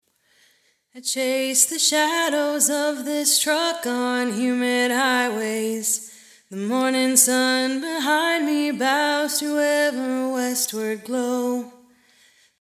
It’s a bathroom preset that gives the voice some space but it also gets in the way of the vocal.
vocalreverb.mp3